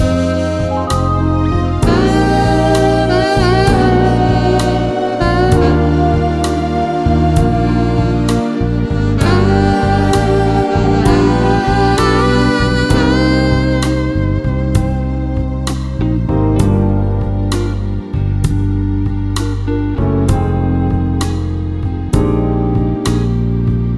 no Backing Vocals Soul / Motown 4:40 Buy £1.50